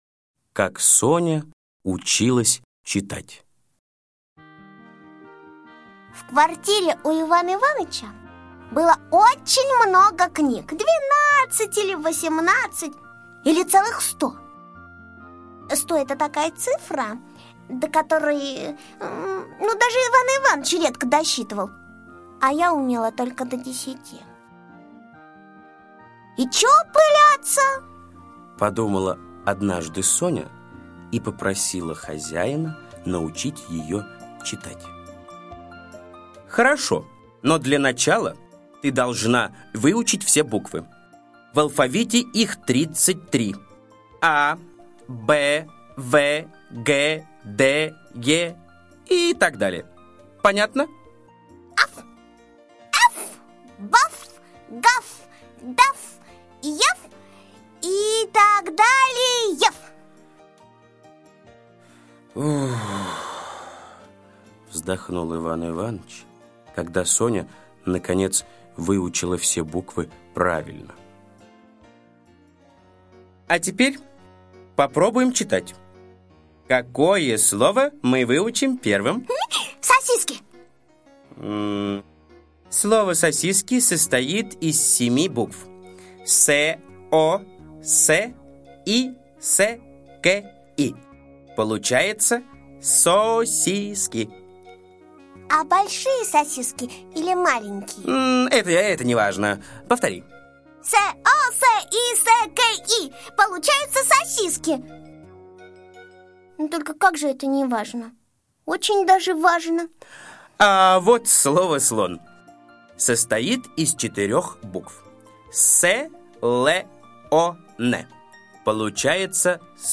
Слушайте Как Соня училась читать - аудиосказка Усачева А.А. Собачка Соня училась читать, но не могла понять: почему слово Слон меньше слова Кошка.